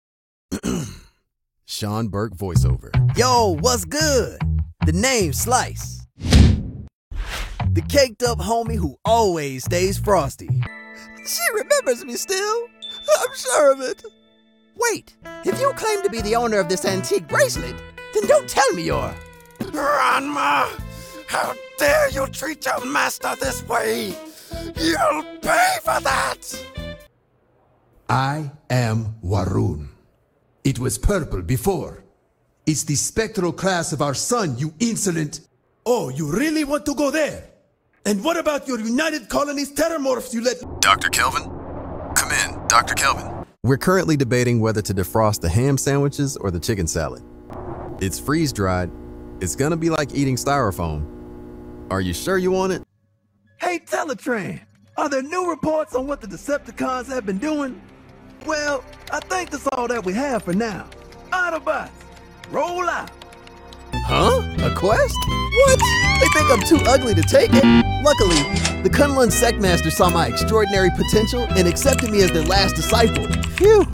Character Demo Reel
Southern American, Neutral American, African, Russian, African American, British
I record from a professional home studio and provide clean, broadcast-ready audio with fast turnaround, clear communication, and attention to detail from start to finish.